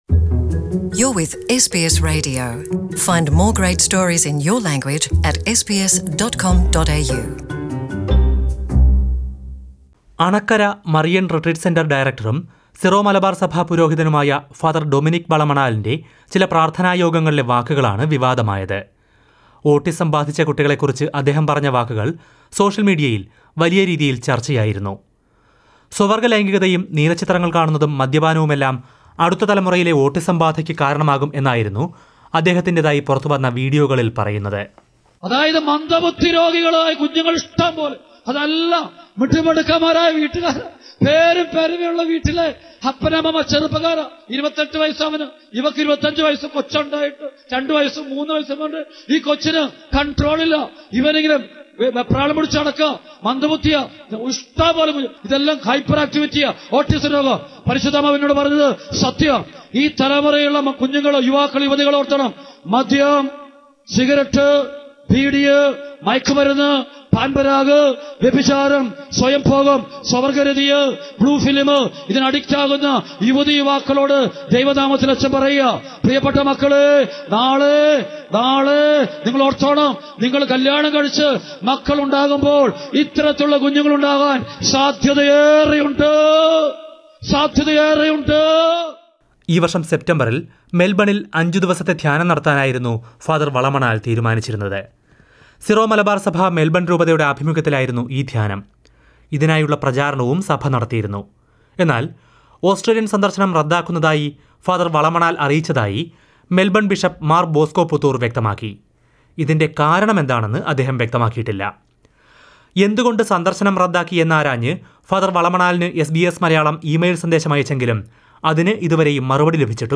Listen to a report in Malayalam